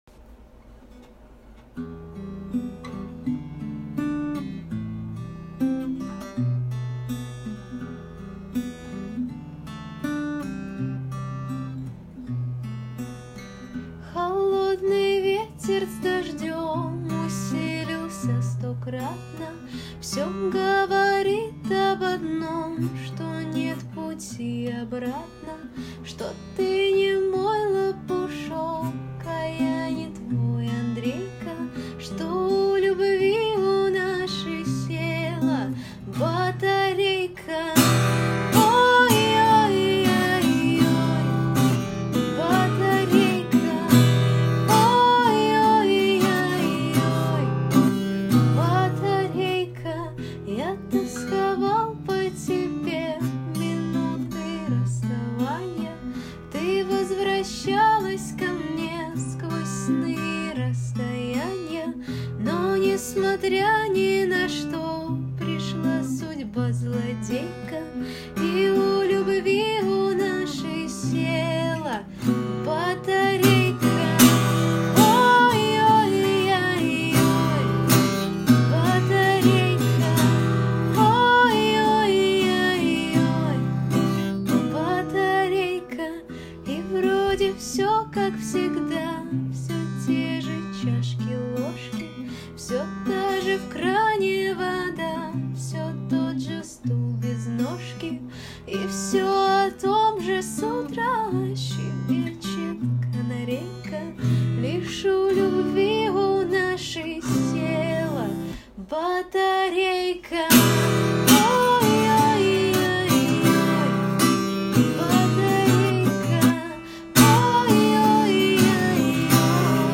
Певческий голос Сопрано